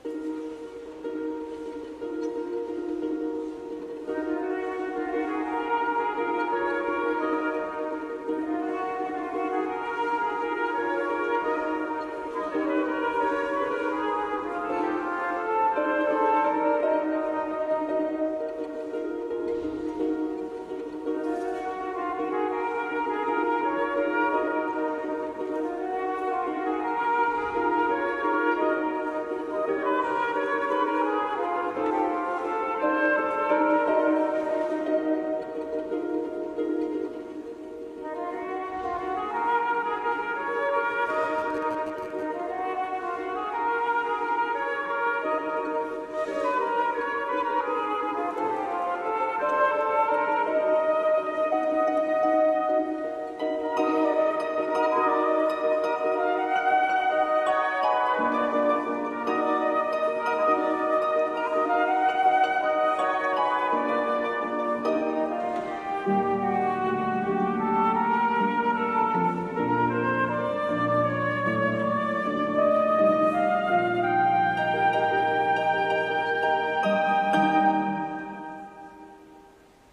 Benjamin Britten – This little Babe z „A Ceremony of Carols” w opr. wł. na flet i harfę